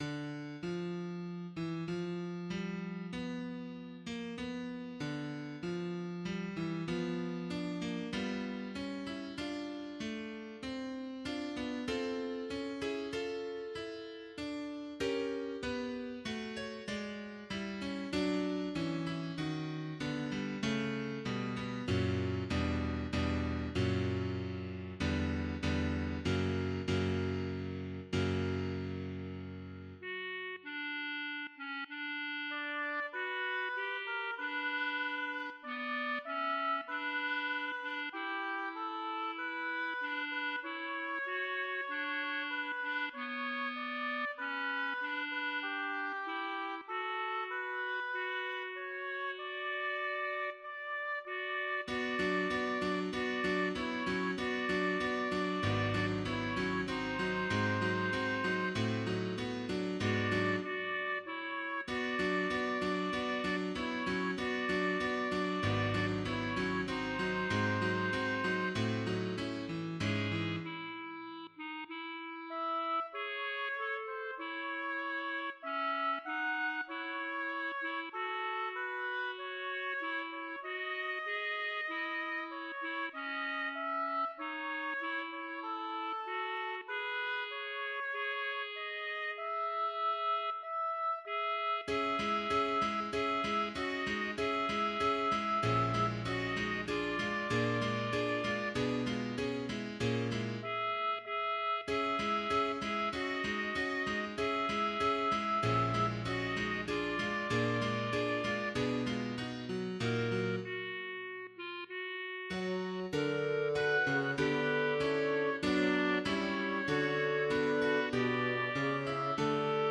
faure_requiem_offertoire_sop.mp3